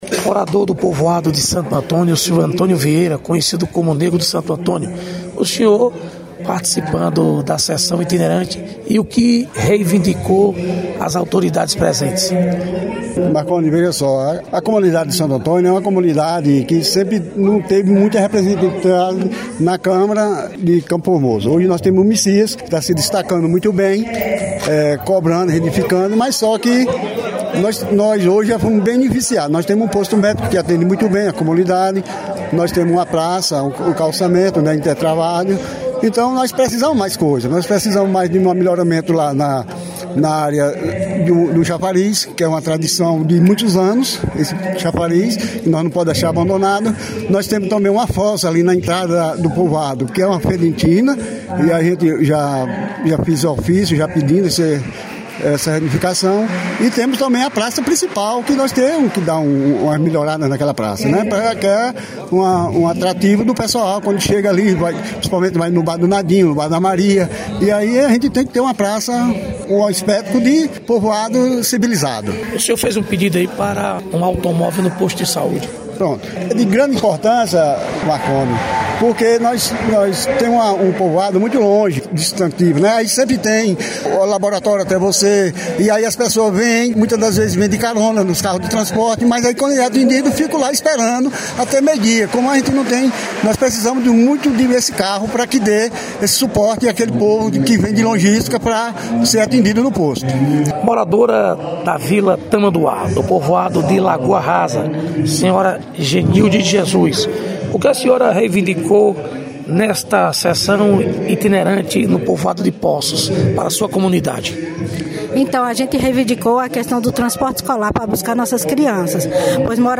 Reportagem: Moradores de Poços comentam a importância da Câmara Itinerante na comunidade